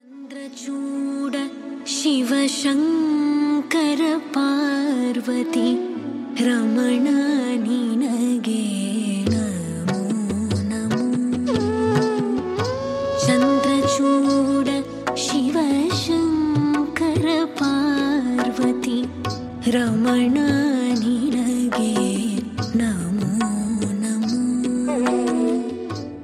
bhakti song ringtone